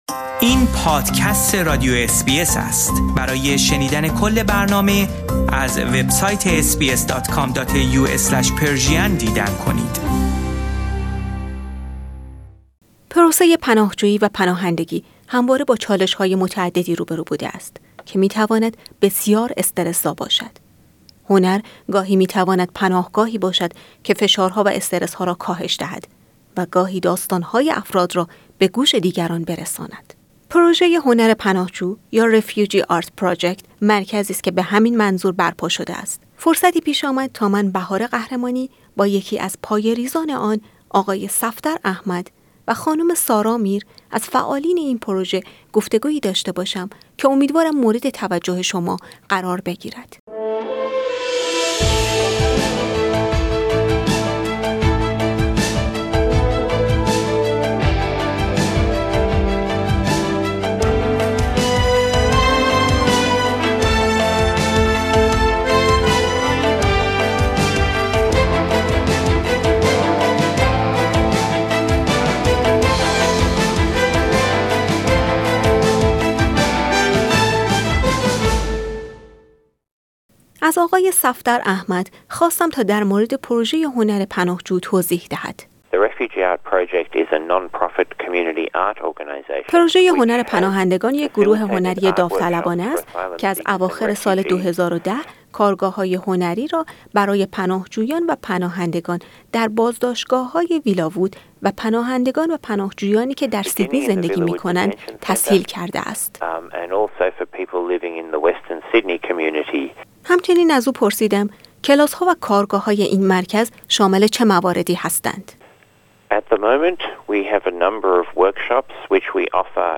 گفتگويي داشته باشیم.